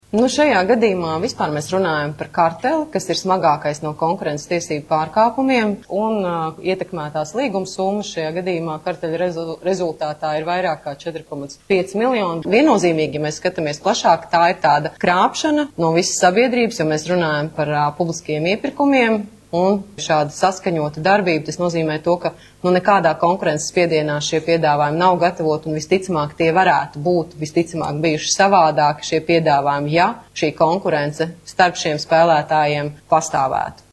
Konkurences padome kostatējusi, ka tas nav bijis vienots pārkāpums starp visiem iesaistītajiem uzņēmumiem, bet gan divas uzņēmēju grupas - Rīgas un Rēzeknes, ietekmējot vairāk nekā 30 iepirkumus visā Latvijas teritorijā, tā šodien preses konferencē paziņoja Konkurences Padomes (KP) priekšsēdētāja pienākumu izpildītāja Ieva Šmite.
Konkurences Padomes priekšsēdētāja pienākumu izpildītāja Ieva Šmite